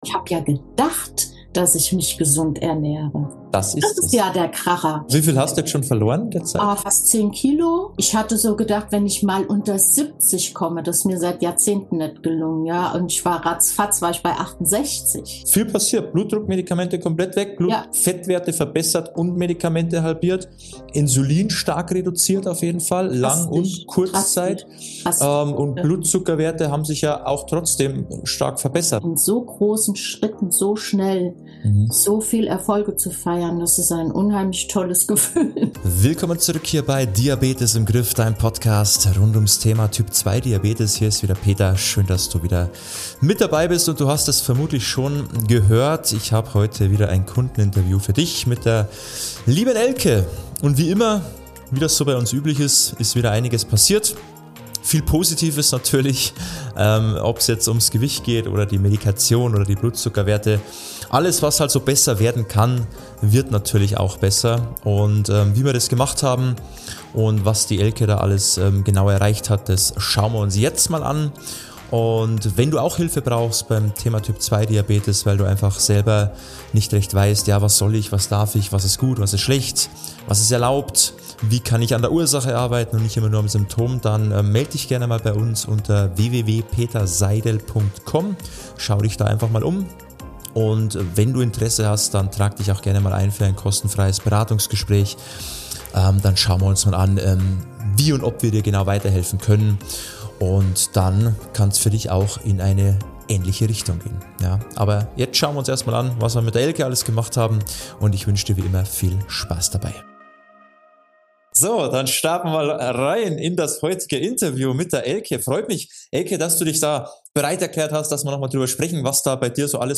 In diesem spannenden Interview